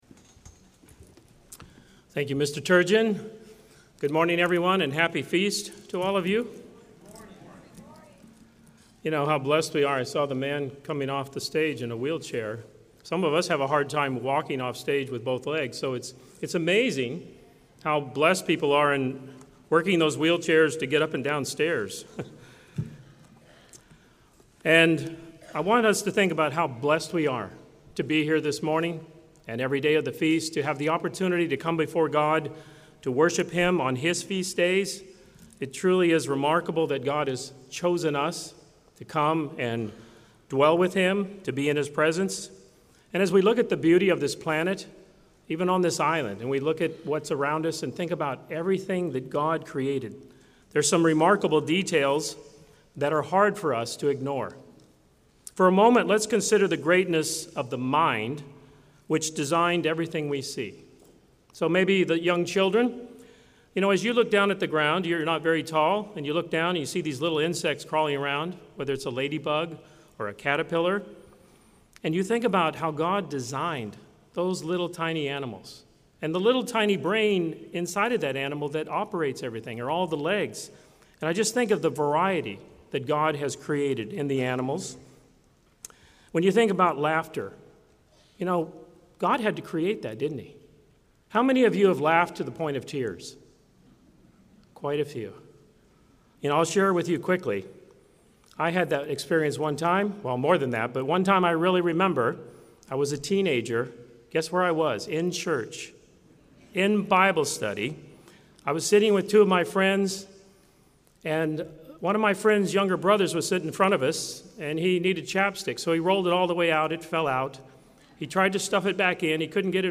This sermon was given at the Jekyll Island, Georgia 2022 Feast site.